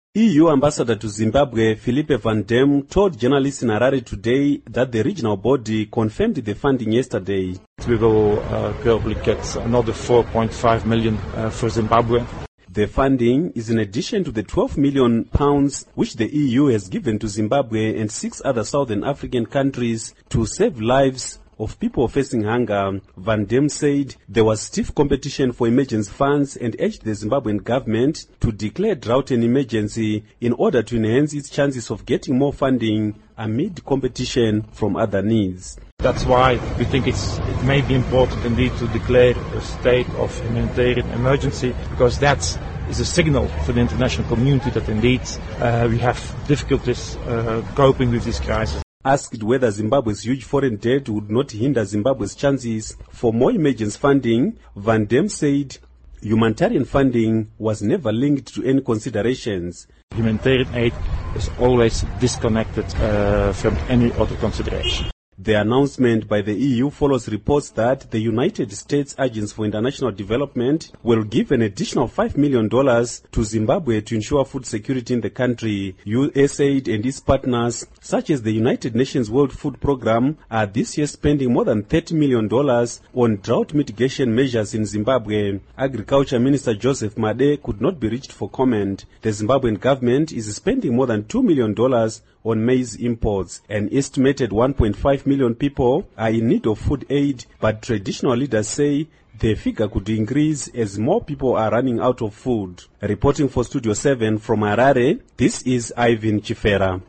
EU ambassador to Zimbabwe, Phillepe Van Demme, told journalists in Harare on Thursday that the regional body confirmed the funding Wednesday.